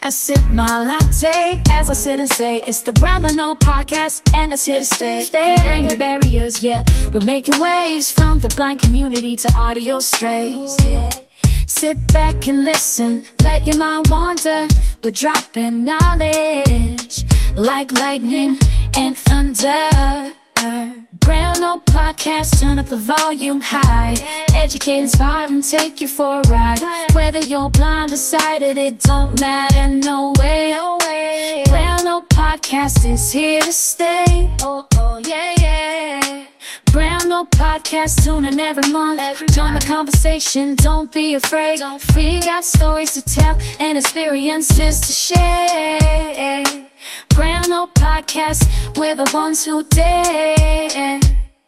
and I already have an AI generated theme song. https